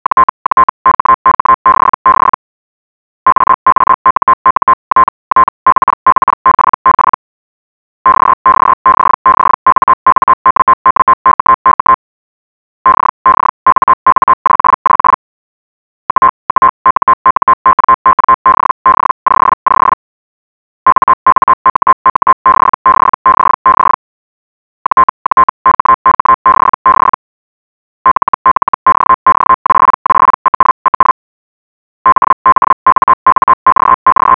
All files are 8 kHz mono, ~34 seconds, generated by HellGen.
Clean at 1050 Hz — tests AFC acquisition537 KB
hell_qbf_1050hz_clean.wav